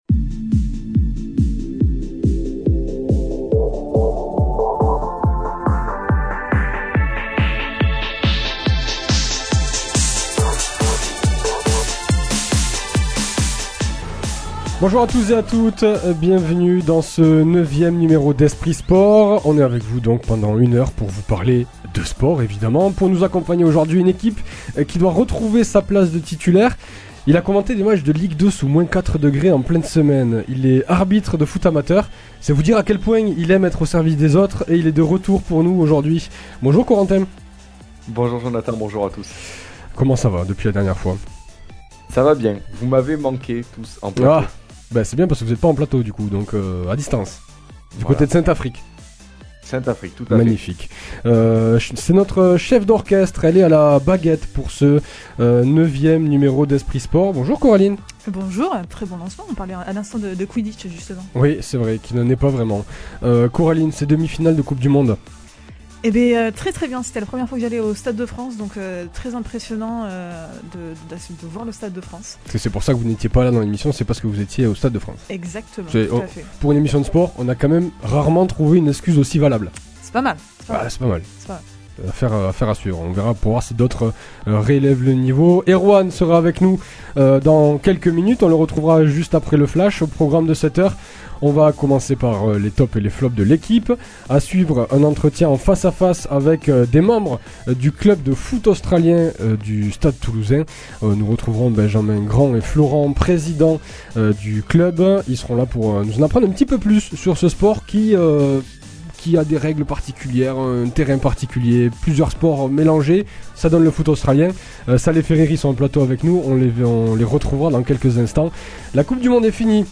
qui sont en plateau avec l’équipe d’Esprit Sport pour parler de leur sport et de leur club.